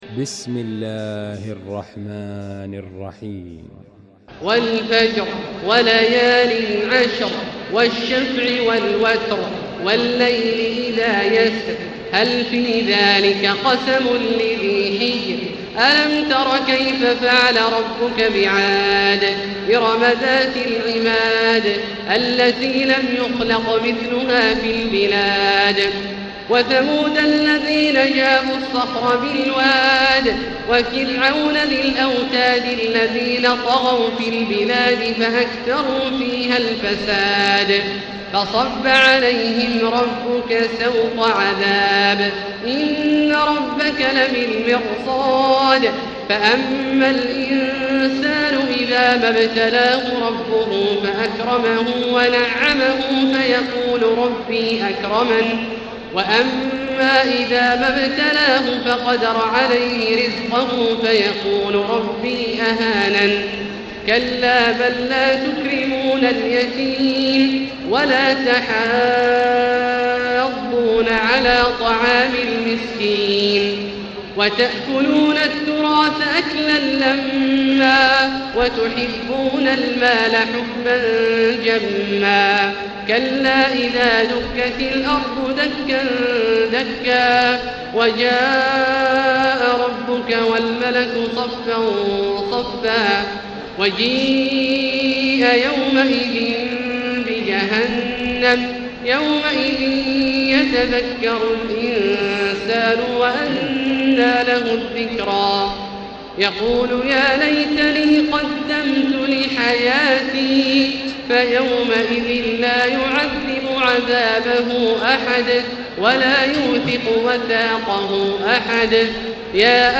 المكان: المسجد الحرام الشيخ: فضيلة الشيخ عبدالله الجهني فضيلة الشيخ عبدالله الجهني الفجر